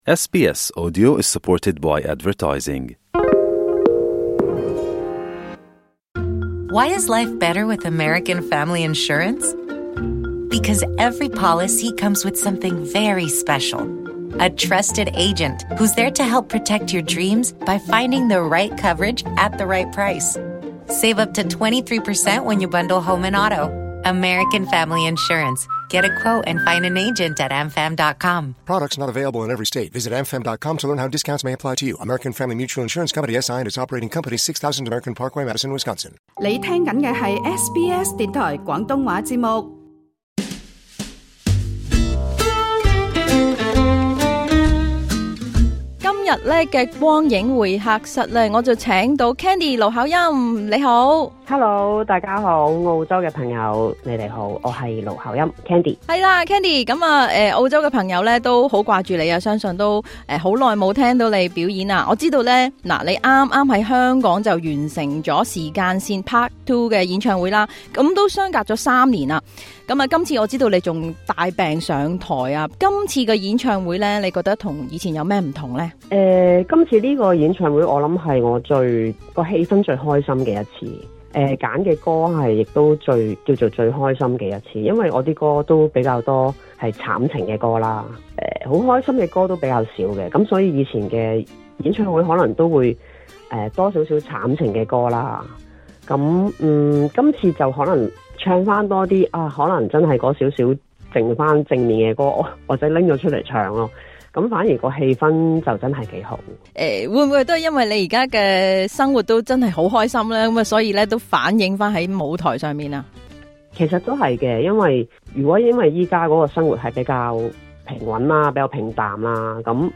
今集《光影會客室》專訪盧巧音，由情緒低谷到跑步重生，半百的她，活出真正奢侈的人生。